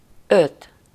Ääntäminen
Ääntäminen France: IPA: [sæ̃ːk] France (Avignon): IPA: [sɛ̃ŋk] Tuntematon aksentti: IPA: /sɛ̃k/ Haettu sana löytyi näillä lähdekielillä: ranska Käännös Ääninäyte Substantiivit 1. öt Suku: m .